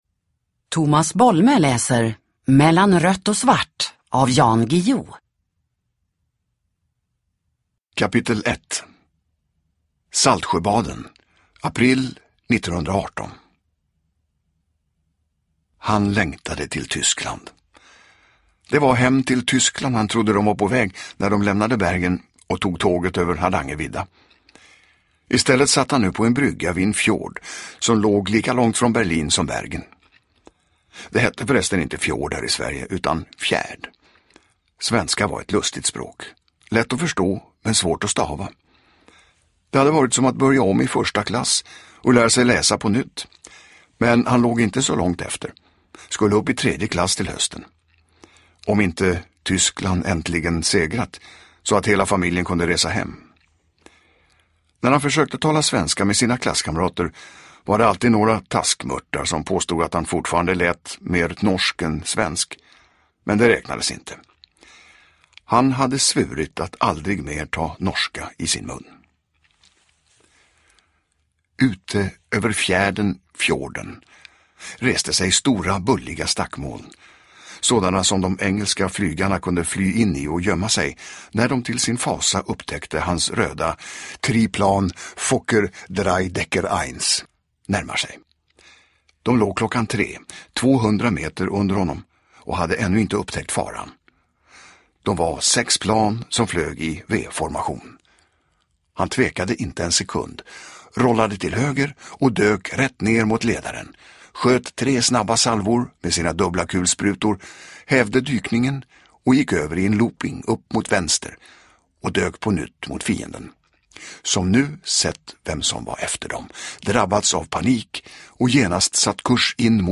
Mellan rött och svart – Ljudbok
Uppläsare: Tomas Bolme